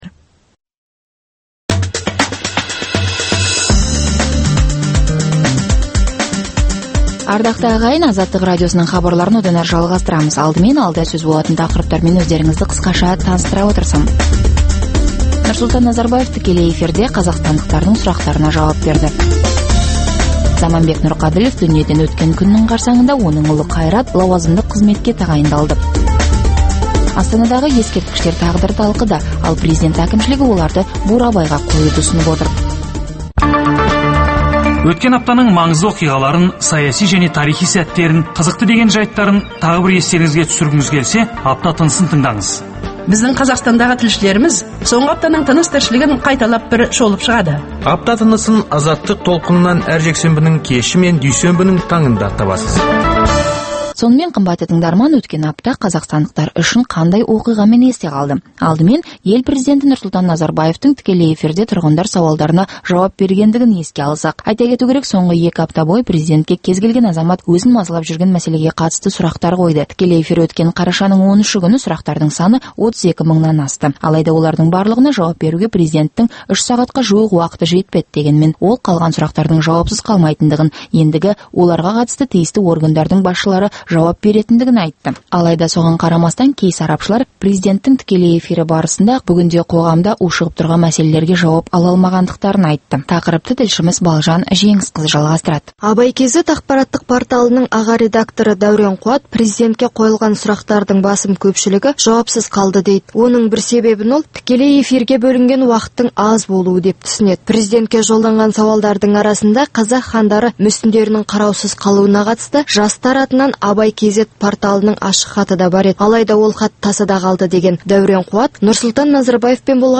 Апта ішінде орын алған елеулі оқиғалар мен өзгеріс, құбылыстар турасында сарапшылар талқылаулары, оқиға ортасынан алынған репортаждардан кейін түйіндеме, пікірталас, қазақстандық және халықаралық талдаушылар пікірі, экономикалық сараптамалар.